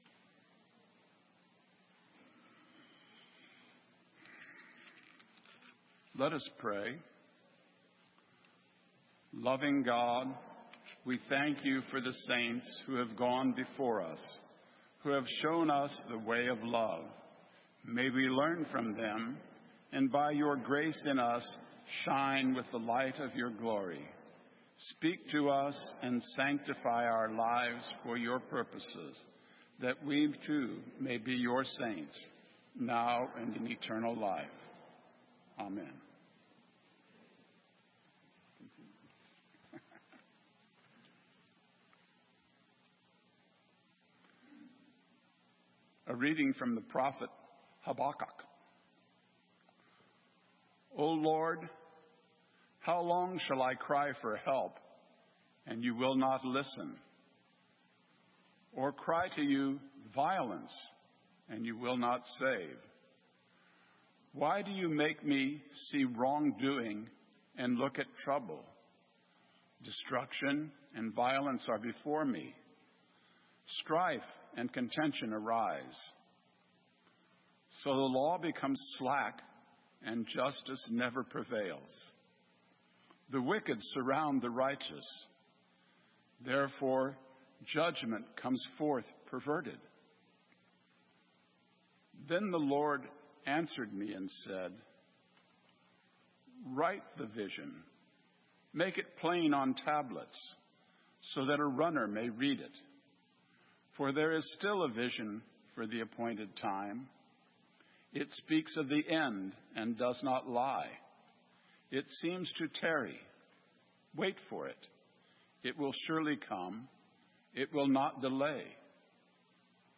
Sermon:Three witnesses - St. Matthews United Methodist Church